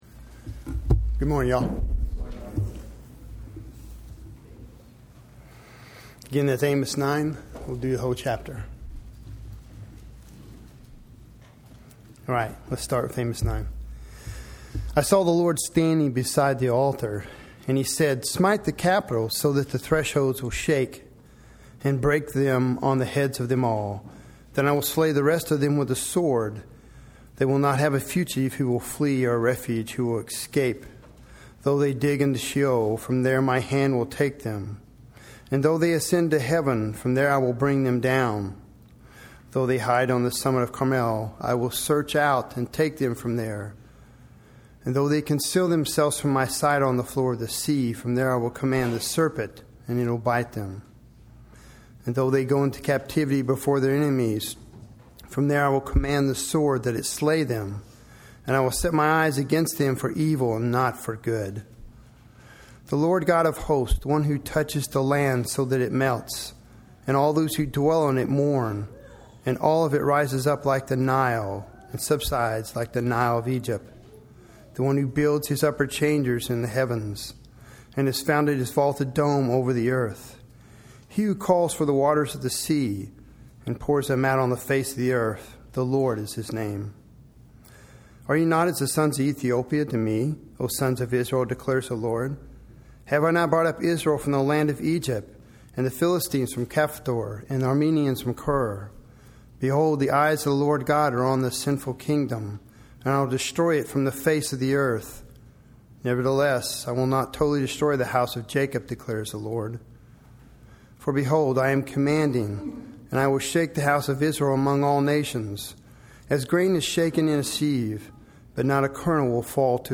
Explore our sermon library below to play, download, and share messages from McKinney Bible Church.